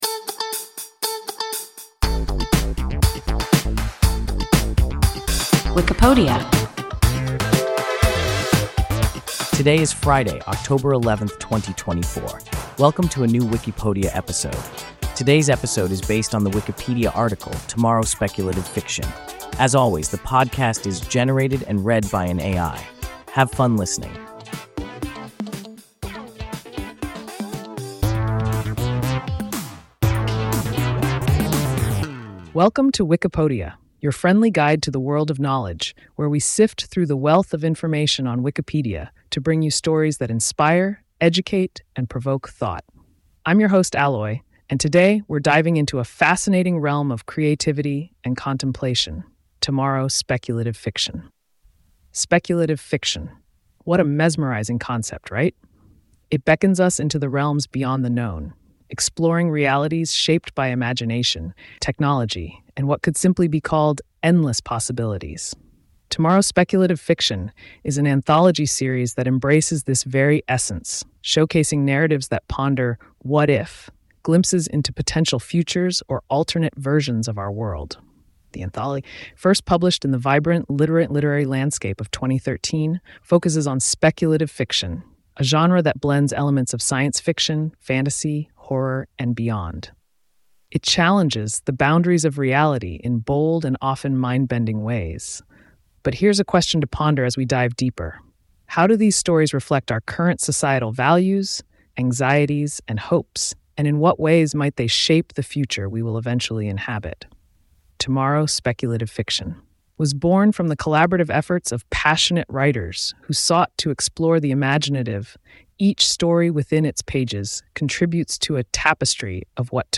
Tomorrow Speculative Fiction – WIKIPODIA – ein KI Podcast